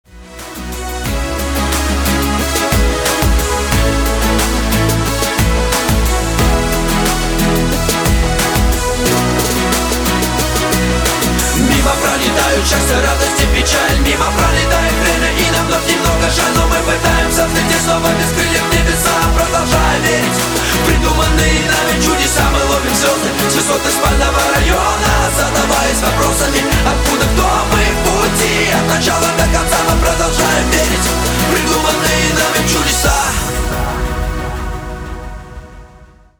• Качество: 320, Stereo
Хип-хоп